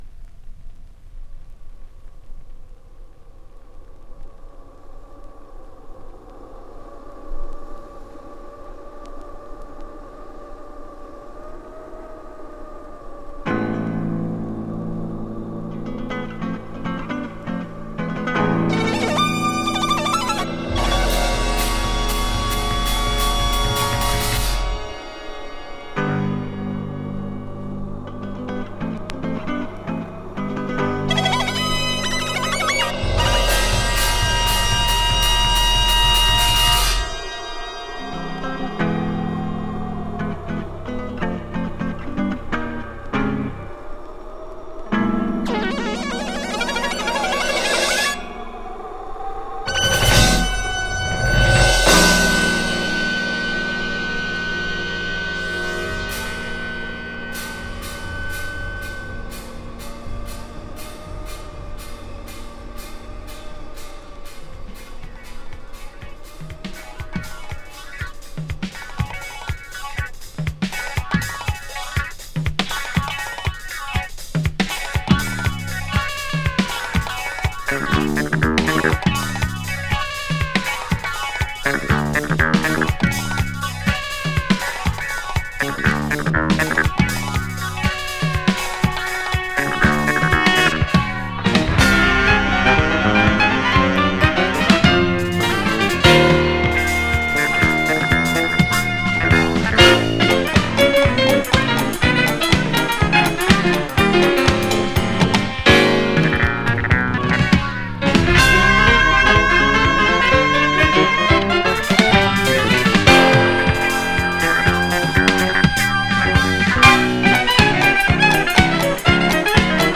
Jazz rock from New York! Keyboard player from New York.
[FUSION] [JAZZ FUNK]